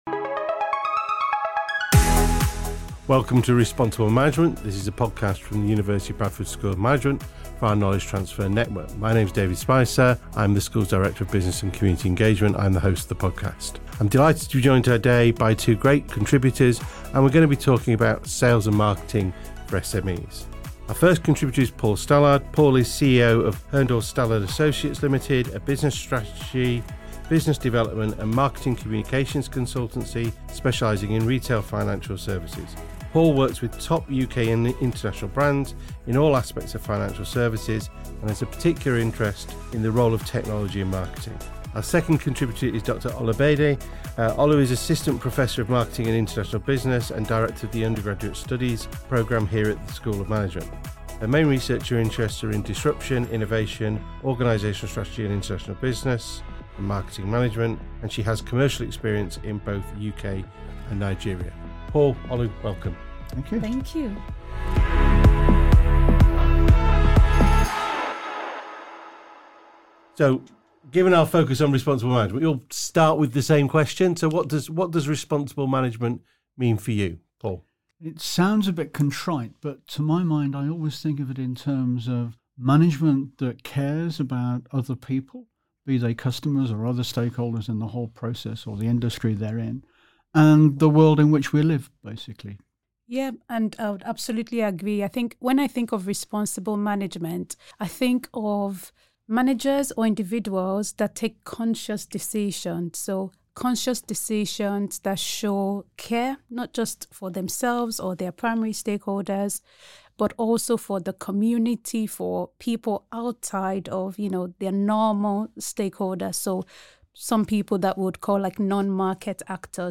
The conversation delves into a range of topics including the key rules for marketing as a startup or entrepreneur, how to focus on the needs of the customer and remembering that companies of all sizes are in the same boat when it comes to marketing.